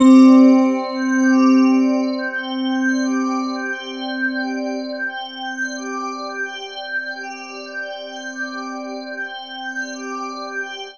Index of /90_sSampleCDs/Chillout (ambient1&2)/11 Glass Atmos (pad)